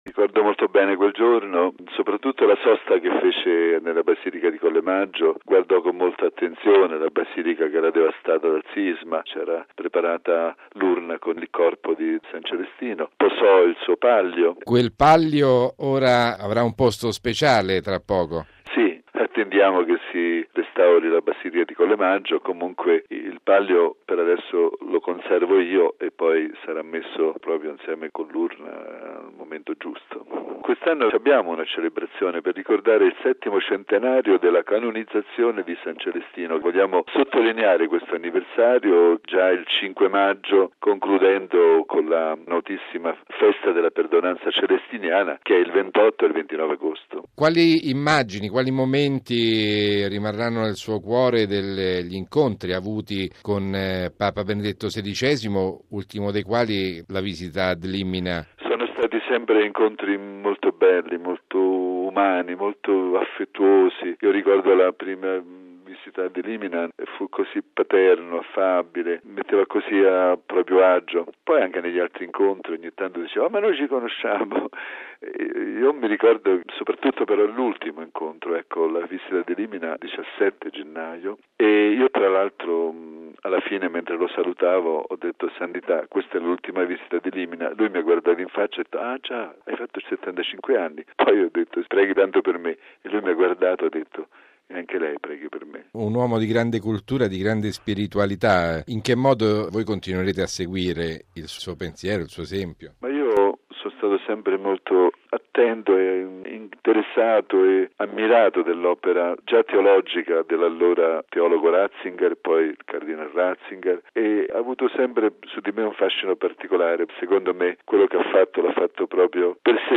ne ha parlato con l’arcivescovo della città, mons. Giuseppe Molinari: